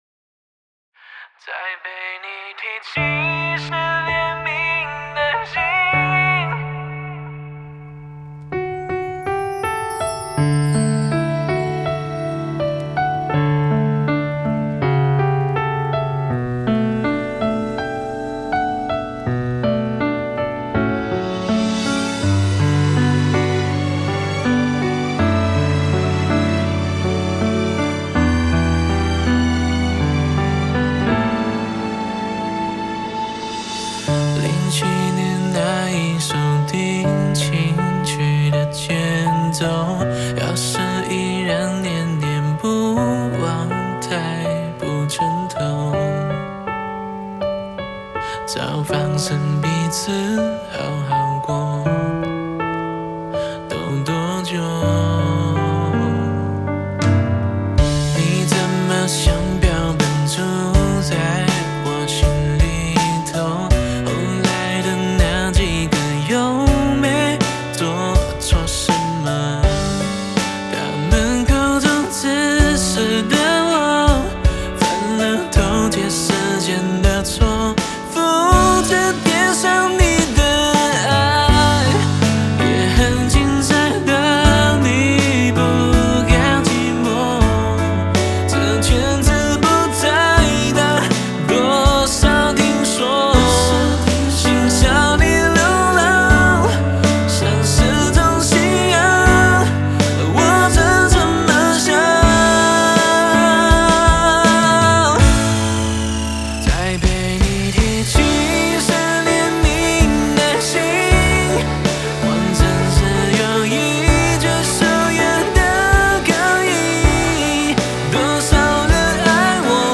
Ps：在线试听为压缩音质节选，体验无损音质请下载完整版